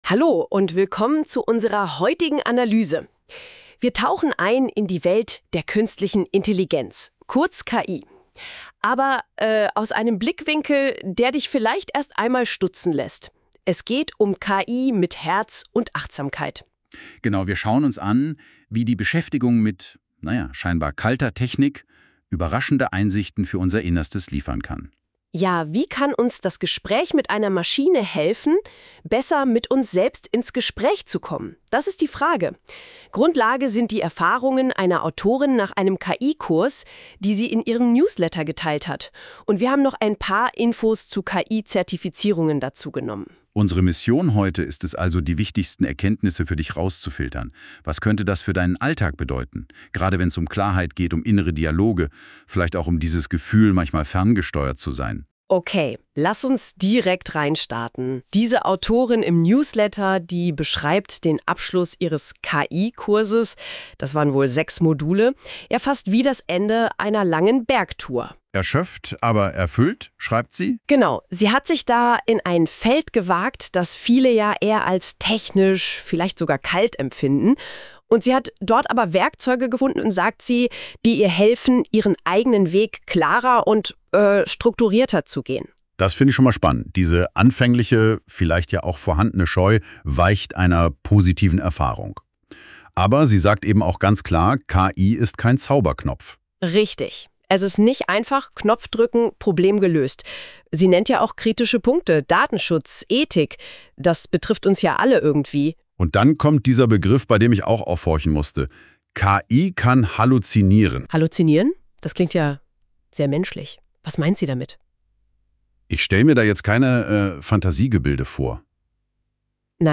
🎧 Audio-Zusammenfassung
Falls du den Artikel lieber hören möchtest: Hier findest du eine kurze Audio-Zusammenfassung mit den wichtigsten Gedanken.